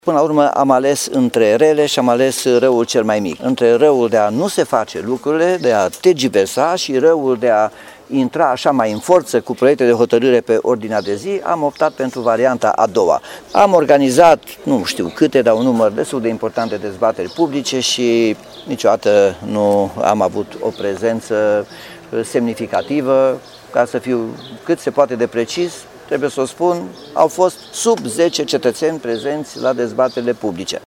Primarul Nicolae Robu îşi asumă doar parţial criticile: